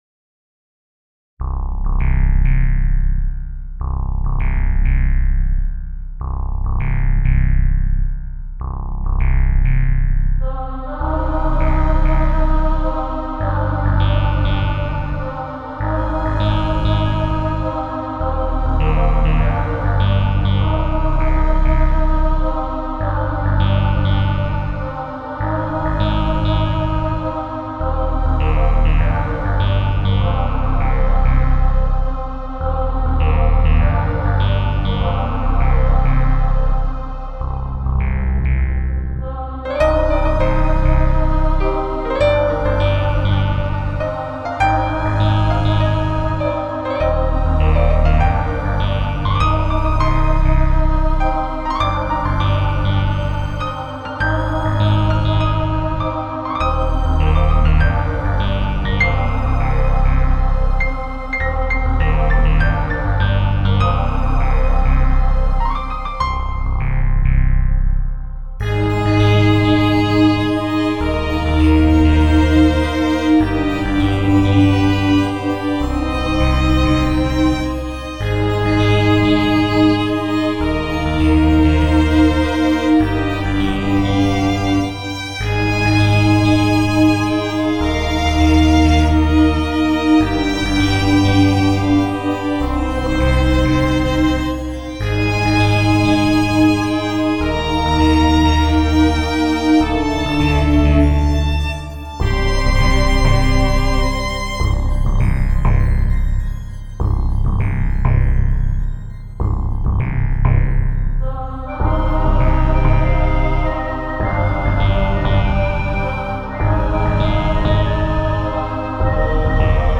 Инструментальная фантазия
Инструментальная музыка